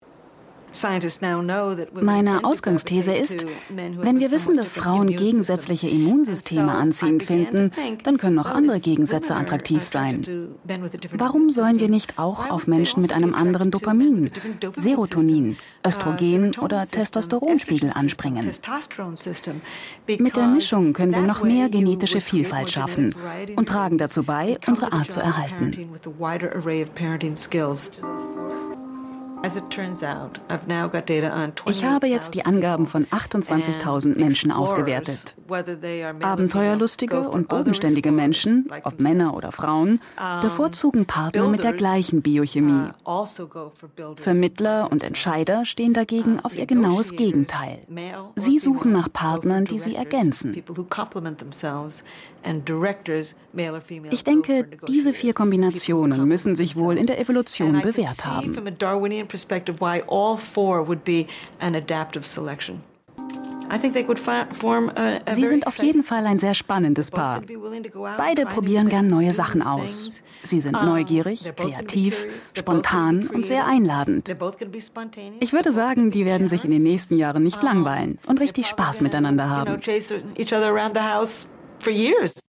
Dokumentation - Voiceover
dynamisch